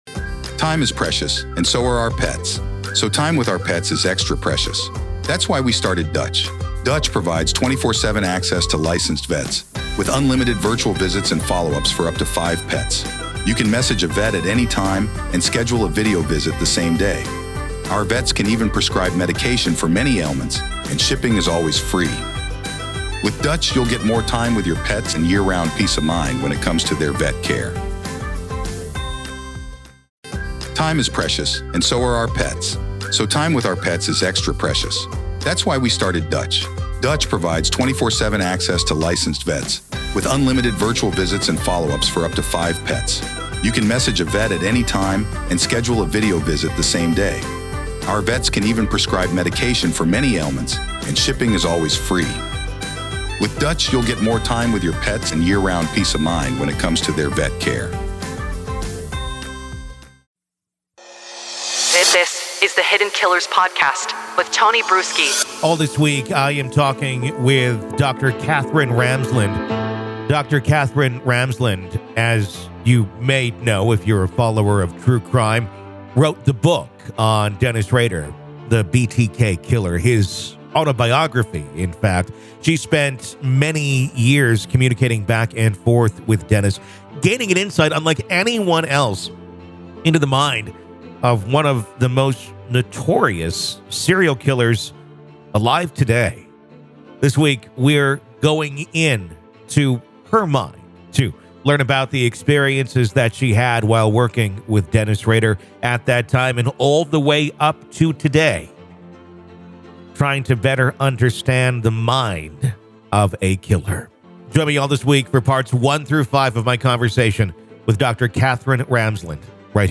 Dr. Katherine Ramsland Interview: Behind The Mind Of BTK Part 5